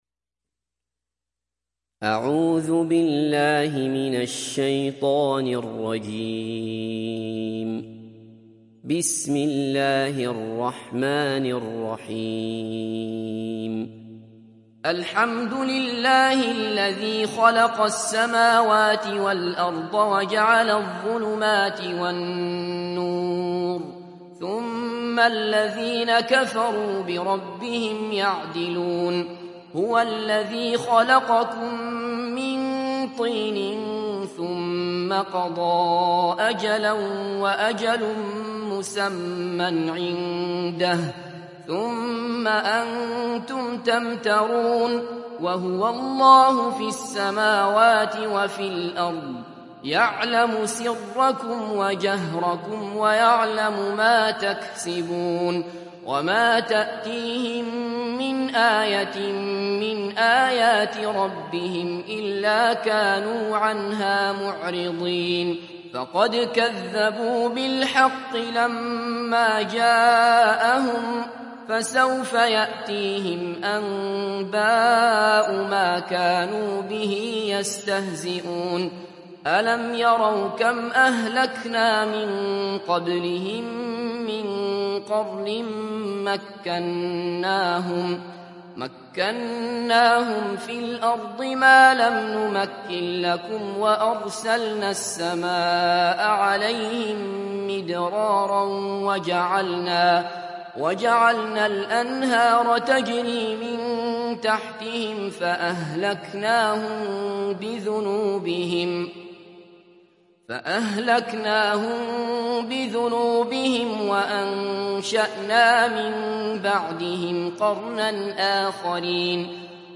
تحميل سورة الأنعام mp3 بصوت عبد الله بصفر برواية حفص عن عاصم, تحميل استماع القرآن الكريم على الجوال mp3 كاملا بروابط مباشرة وسريعة